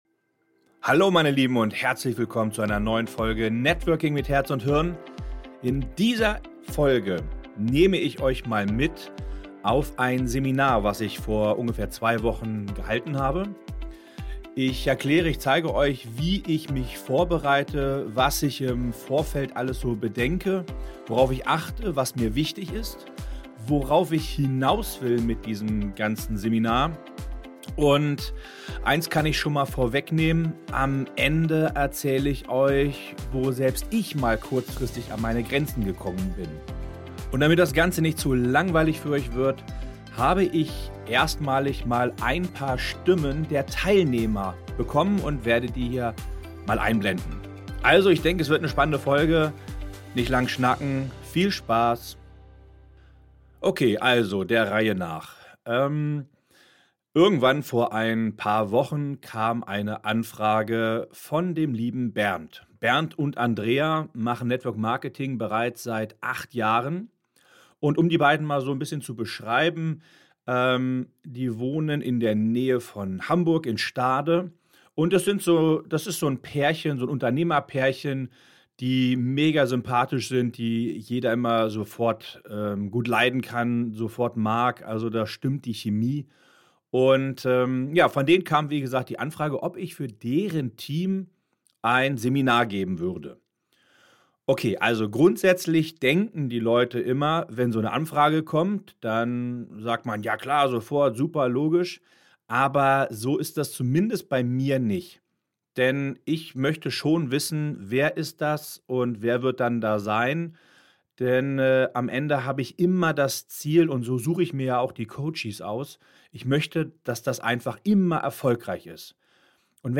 Gespickt ist diese Folge zudem mit Stimmen direkt aus dem Seminar.